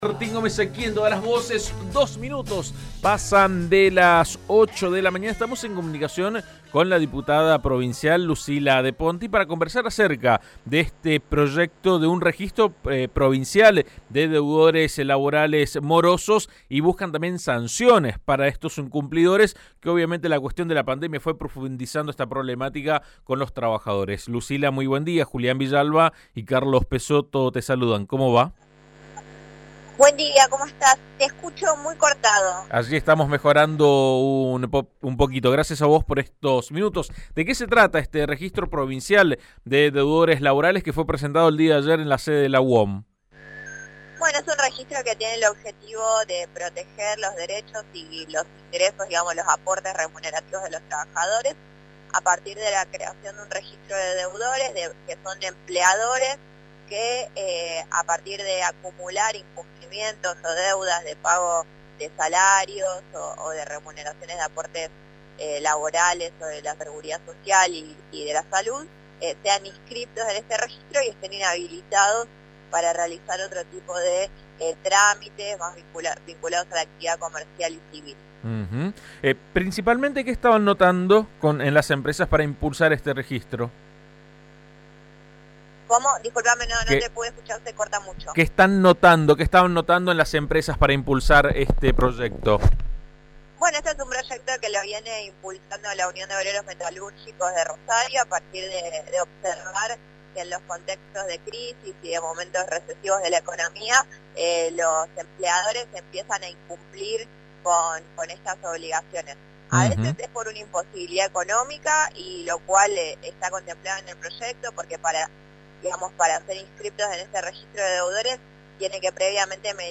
La herramienta establecería sanciones para los empresarios que incumplan con los trabajadores. La diputada De Ponti lo explicó en AM 1330 (audio)